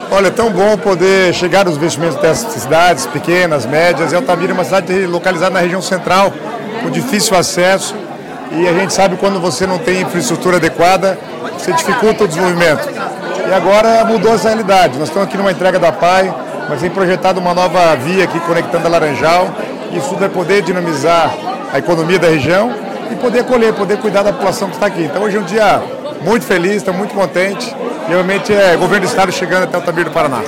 Sonora do secretário das Cidades, Guto Silva, sobre a inauguração da Apae em Altamira do Paraná